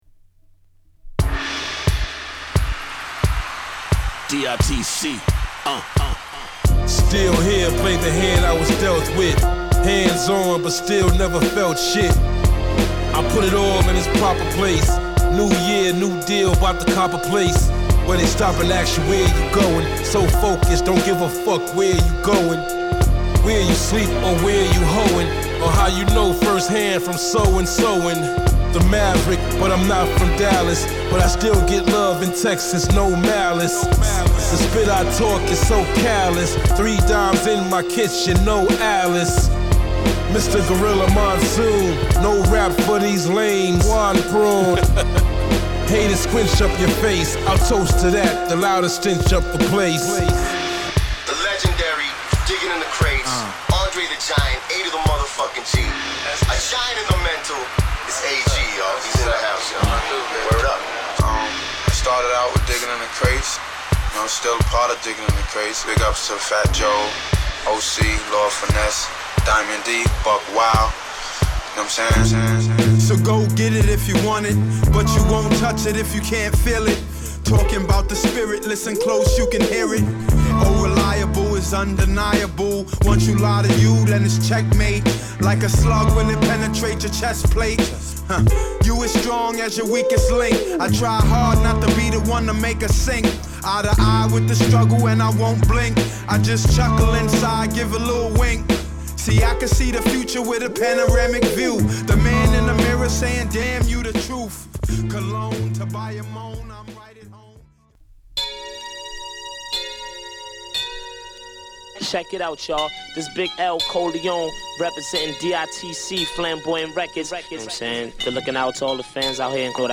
哀愁を漂わせたプリモビーツをインストと共に収録！